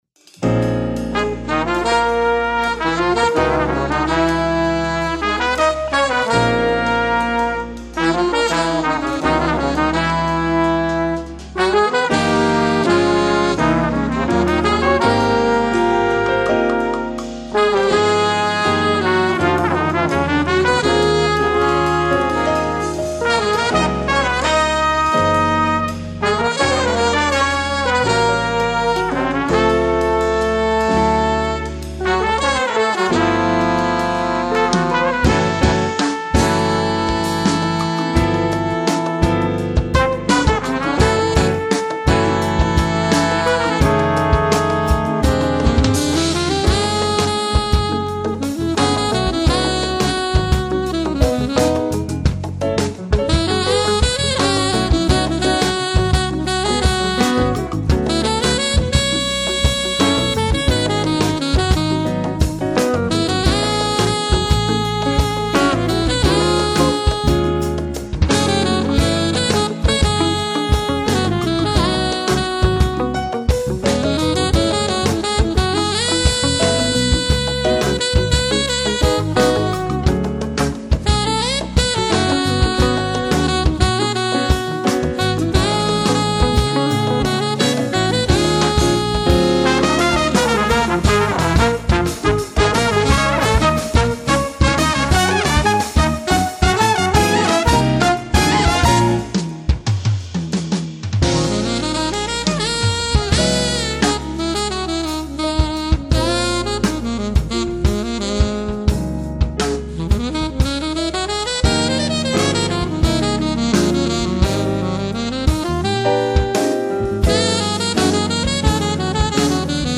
latin fusion octet
sax
trumpet
trombone
guitar
drums
percussion